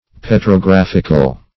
petrographical - definition of petrographical - synonyms, pronunciation, spelling from Free Dictionary
Search Result for " petrographical" : The Collaborative International Dictionary of English v.0.48: Petrographic \Pet`ro*graph"ic\, Petrographical \Pet`ro*graph"ic*al\, a. Pertaining to petrography.